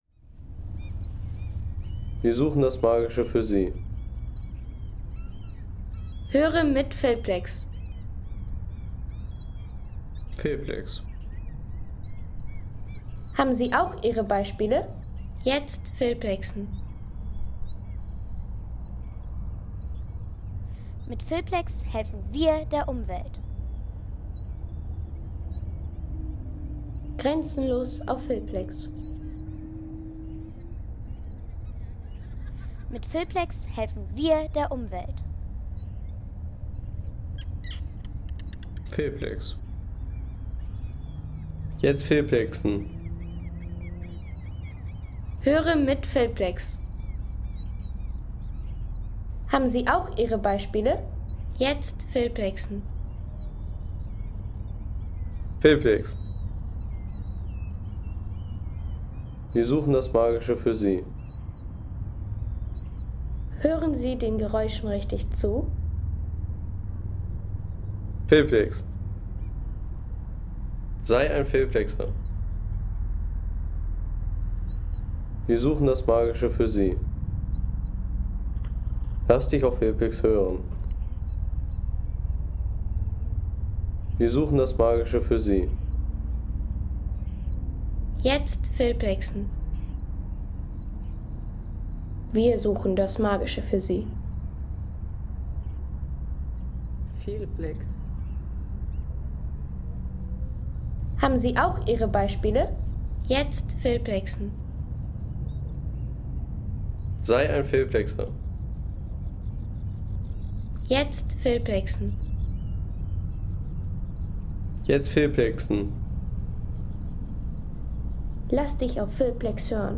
Schwerter im Felsen – Klangkulisse am historischen Monument bei Stav ... 3,50 € Inkl. 19% MwSt.